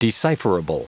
Prononciation du mot decipherable en anglais (fichier audio)